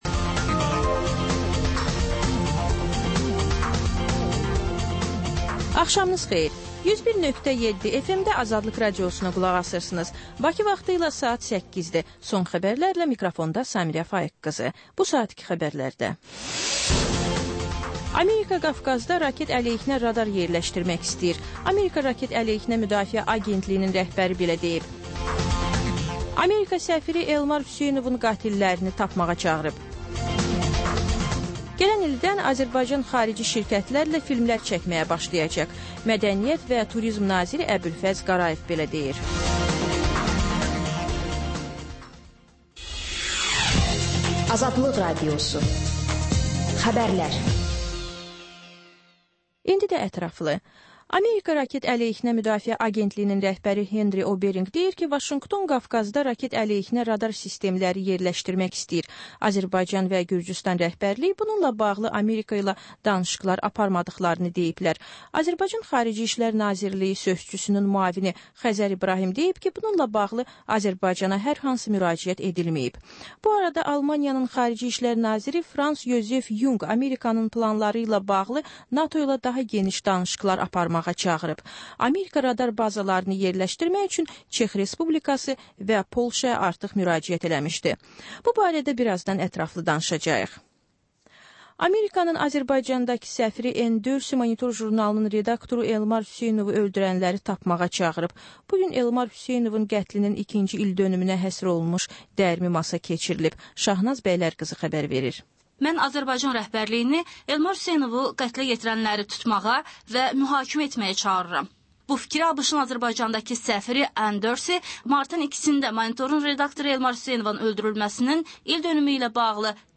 Xəbər, reportaj, müsahibə. Sonra: Günün Söhbəti: Aktual mövzu barədə canlı dəyirmi masa söhbəti.